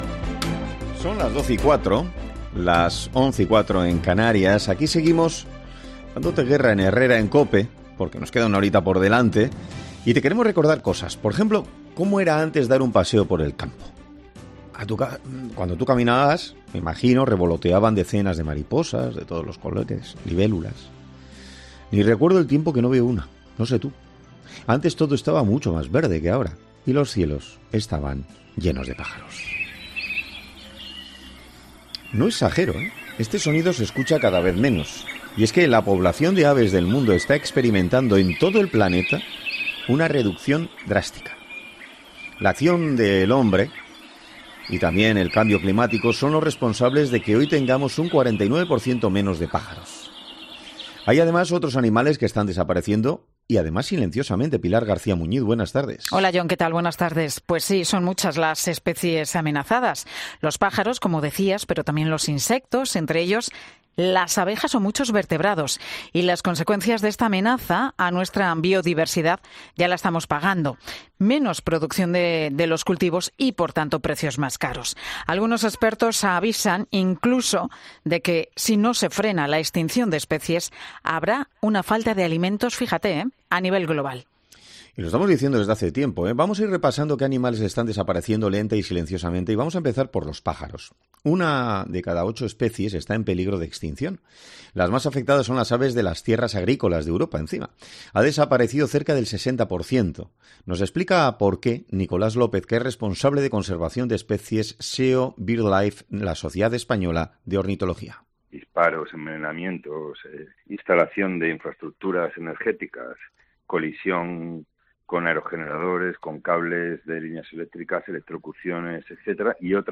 'Herrera en COPE' ha contado con un experto